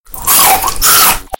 kitty_cat_cronch.mp3